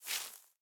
Minecraft Version Minecraft Version 1.21.5 Latest Release | Latest Snapshot 1.21.5 / assets / minecraft / sounds / block / big_dripleaf / tilt_up3.ogg Compare With Compare With Latest Release | Latest Snapshot
tilt_up3.ogg